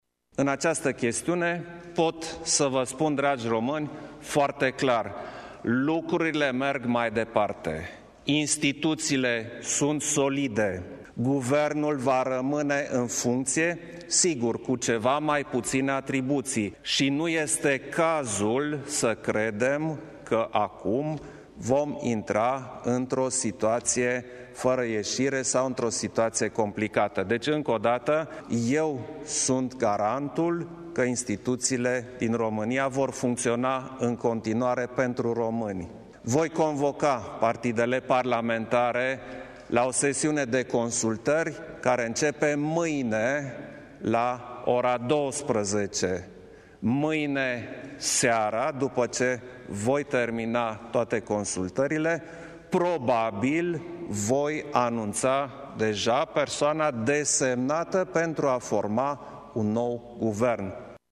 Klaus Iohannis a dat asigurări că instituțiile statului vor funcționa în perioada următoare: